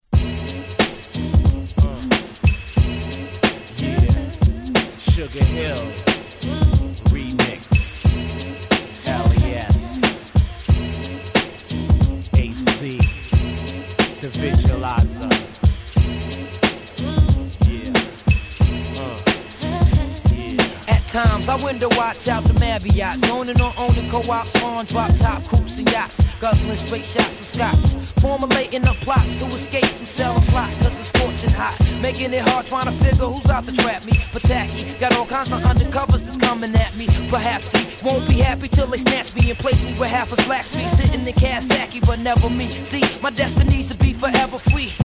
95' Hip Hop Classic !!